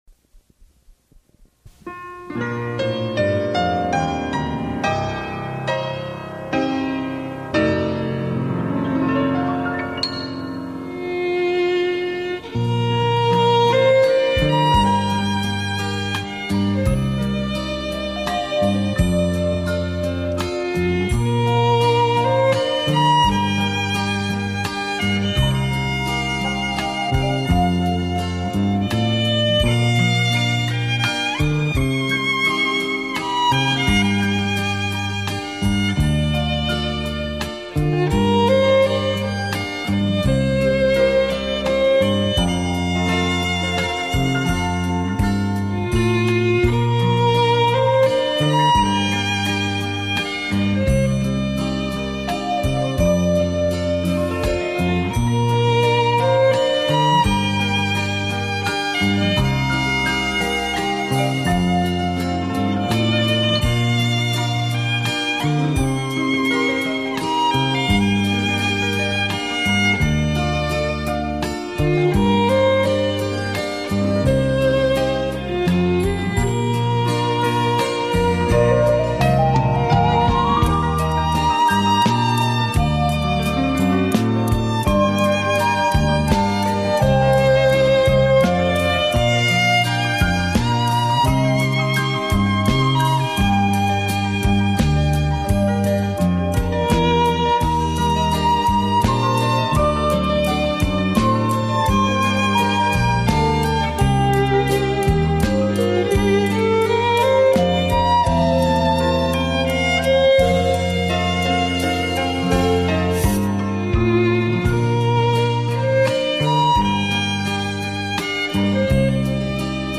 小提琴被誉为“乐器之后”，音色温暖、纯净甜美、极近女声。